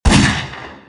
pistol3.mp3